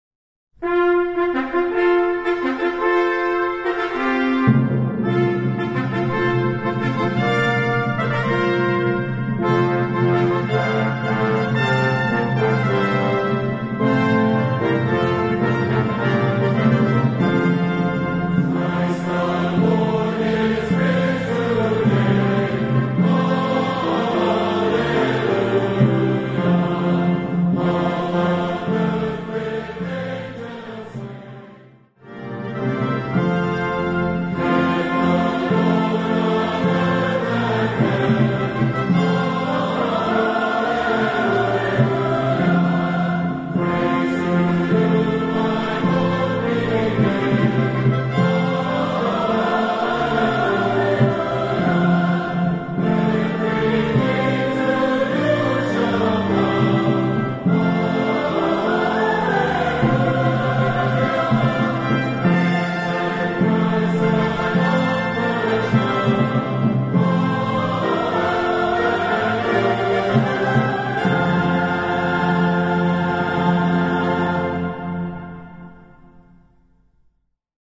Voicing: SATB or Congregation